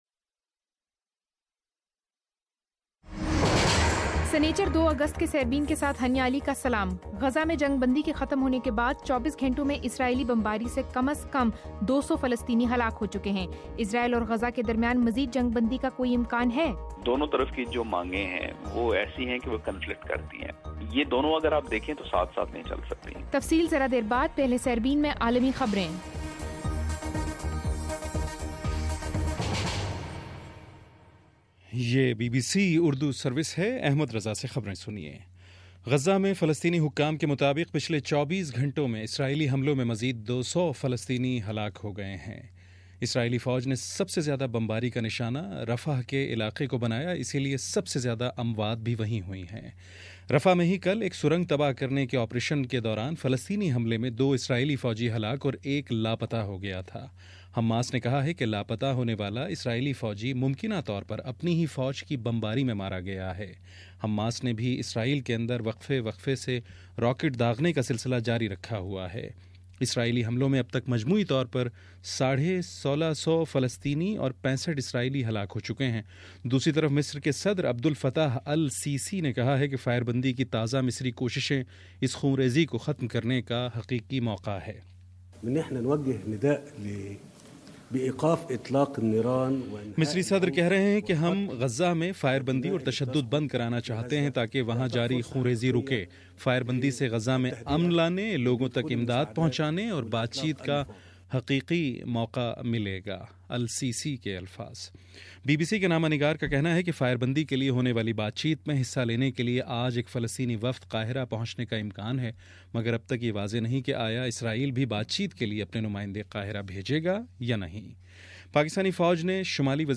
سنیچر 2 اگست کا سيربین ریڈیو پروگرام
بی بی سی اردو کا فلیگ شپ ریڈیو پروگرام روزانہ پاکستانی وقت کے مطابق رات آٹھ بجے پیش کیا جاتا ہے جسے آپ ہماری ویب سائٹ، اپنے موبائل فون، ڈیسک ٹاپ، ٹیبلٹ یا لیپ ٹاپ پر سن سکتے ہیں۔